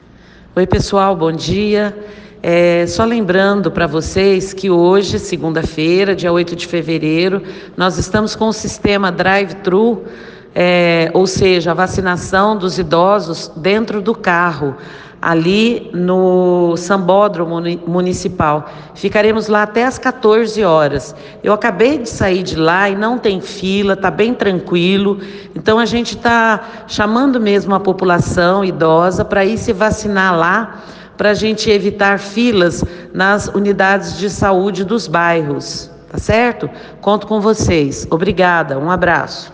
A secretária de Saúde convida a população para a vacinação no sambódromo, que segue até às 14h.
Silvéria Maria Peixoto Laredo - Secretária de Saúde -